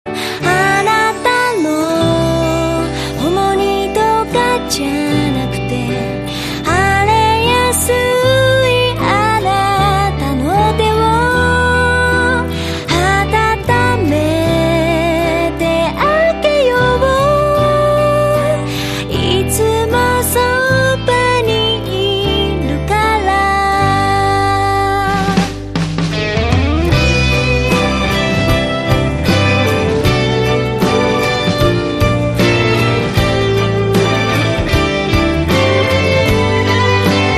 日韩歌曲